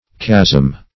Chasm \Chasm\ (k[a^]z'm), n. [L. chasma, Gr. cha`sma, fr.